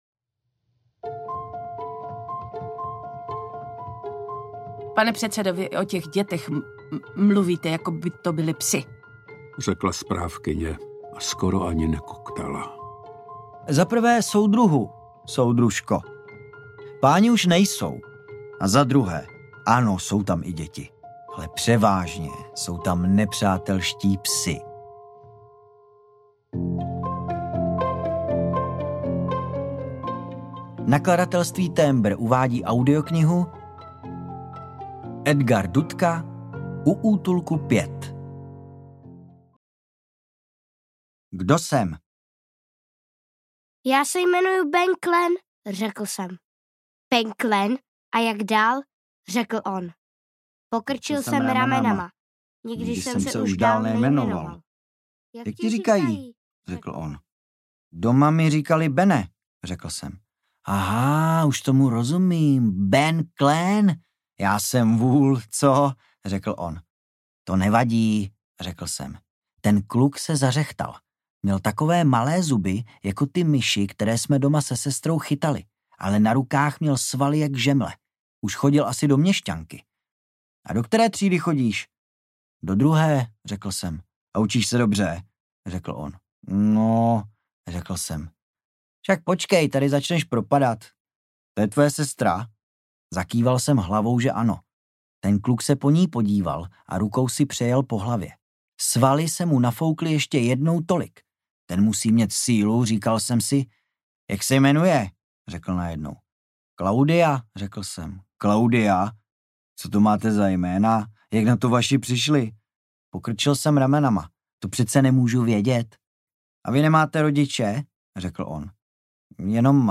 U útulku 5 audiokniha
Ukázka z knihy
Natočeno ve studiu S Pro Alfa CZ